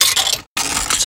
Insert Coin Sound
household